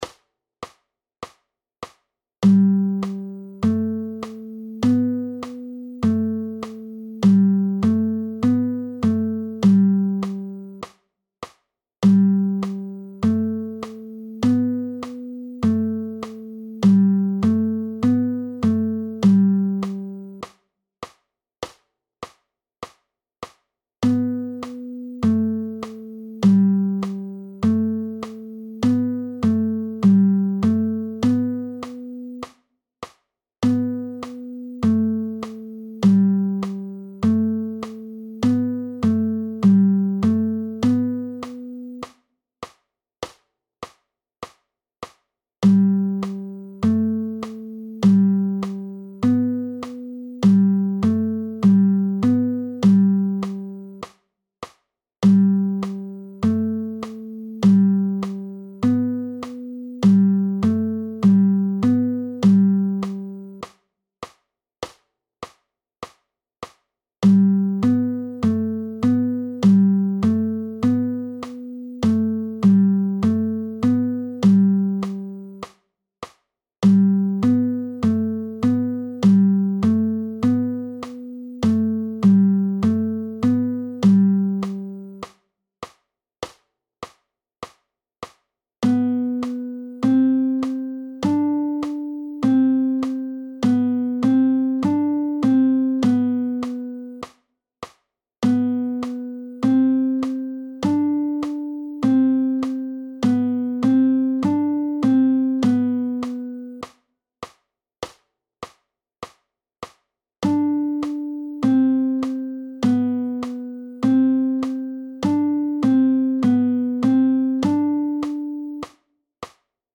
Audio 100 bpm: